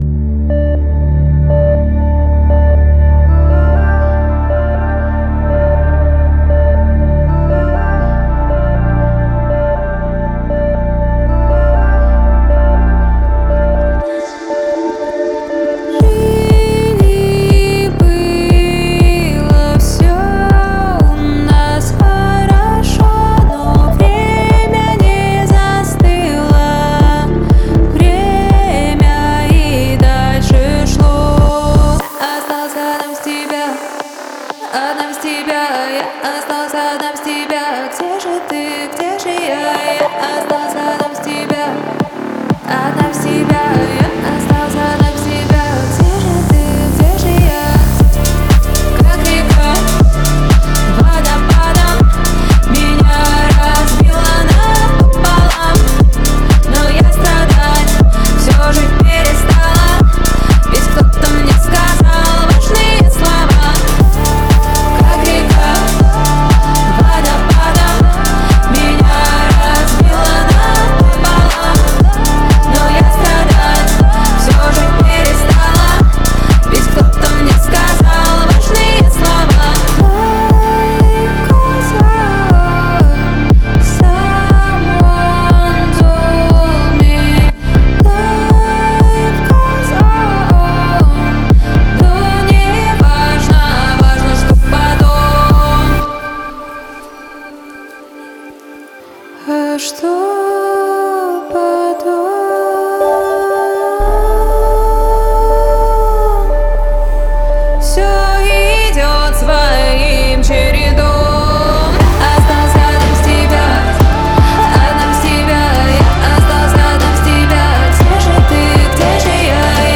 • Жанр: Альтернатива, Русская музыка